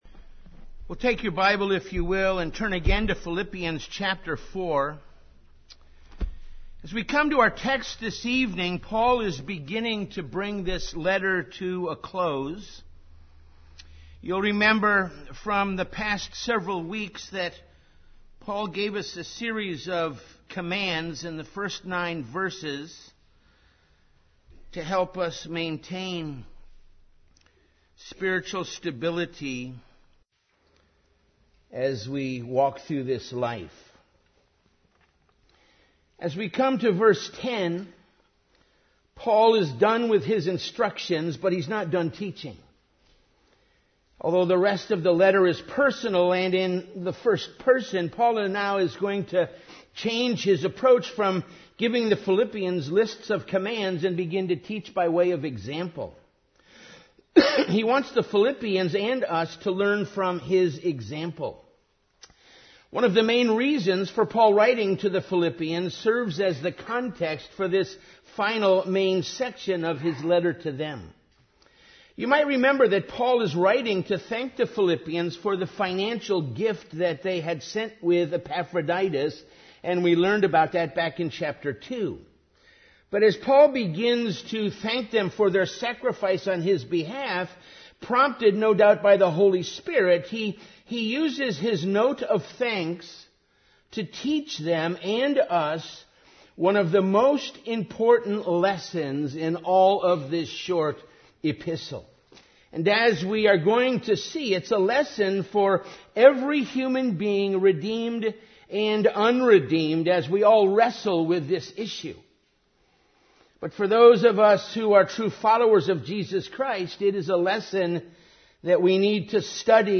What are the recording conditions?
Evening Worship